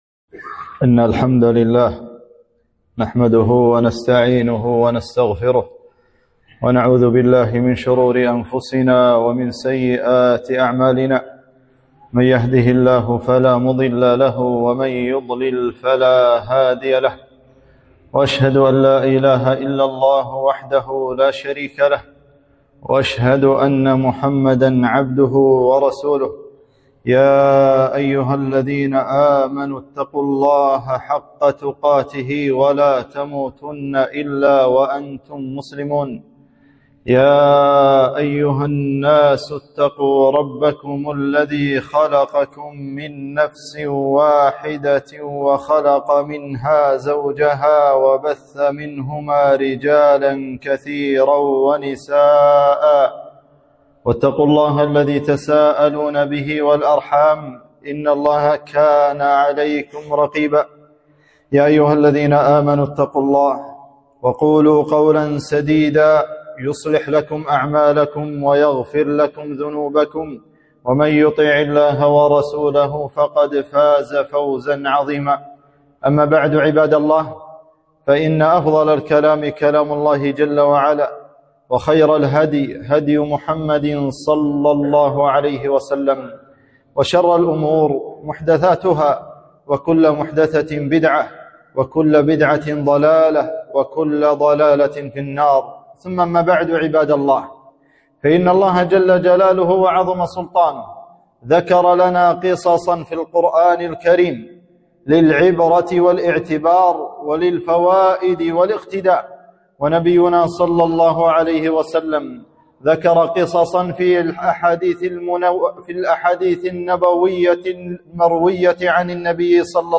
خطبة - من فوائد قصة جريج العابد